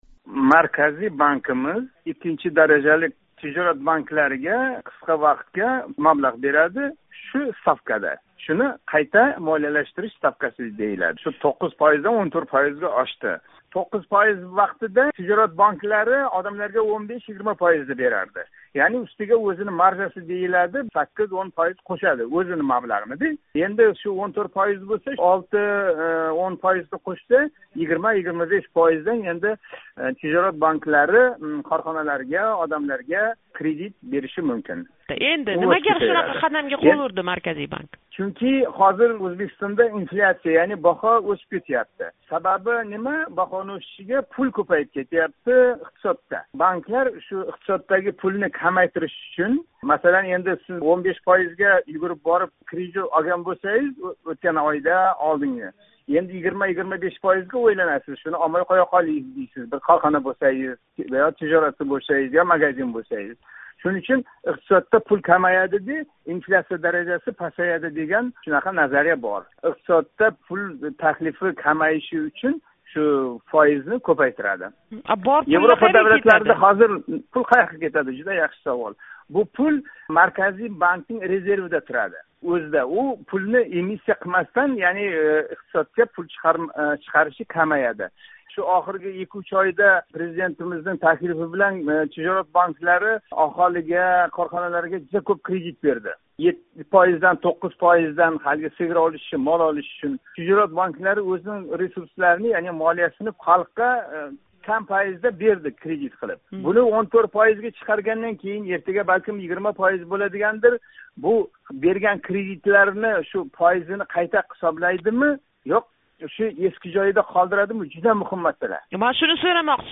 Марказий банк қарори хусусида суҳбат